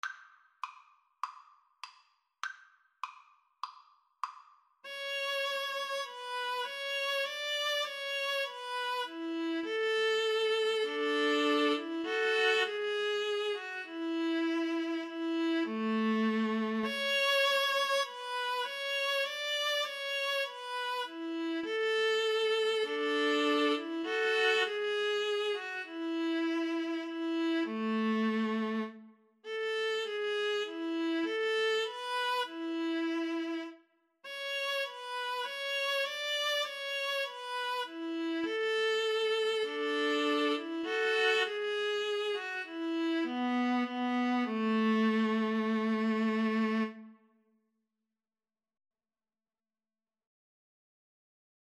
Moderato
Traditional (View more Traditional Violin-Viola Duet Music)